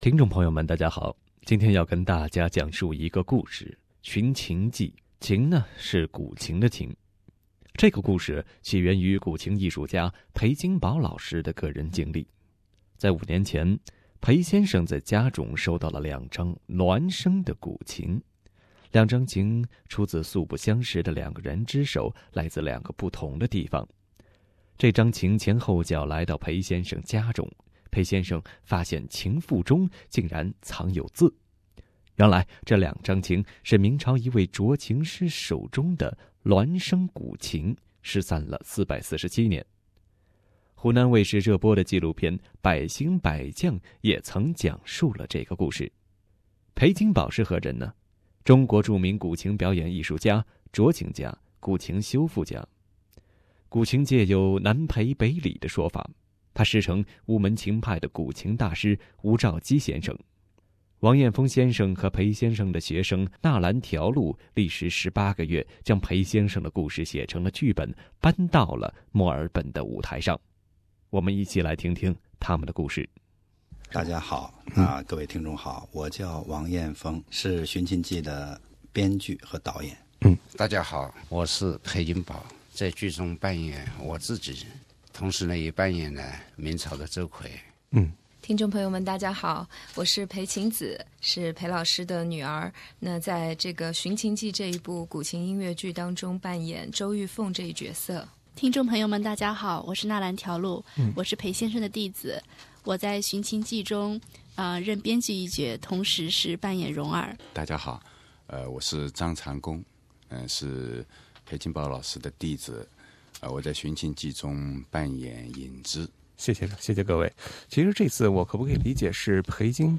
《寻琴记》的主要演员及创作者作客了我们的演播厅，我们一起来听听他们的故事。